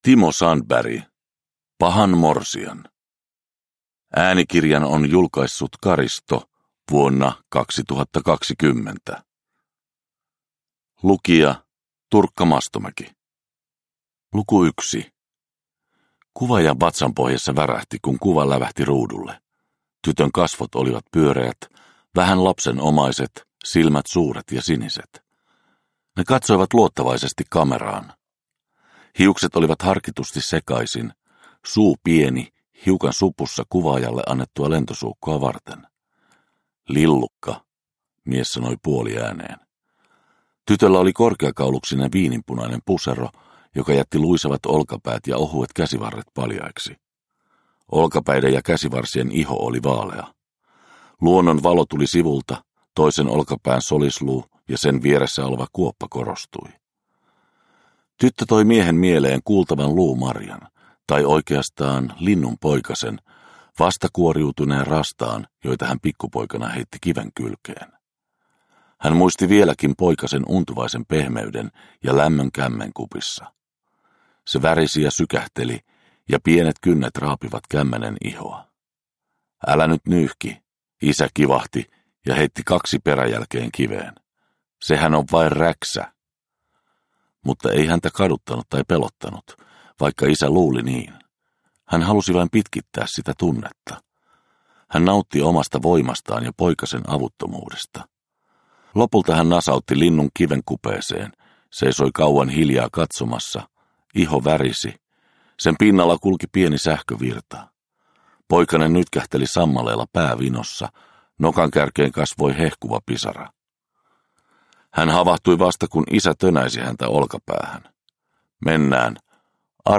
Pahan morsian – Ljudbok – Laddas ner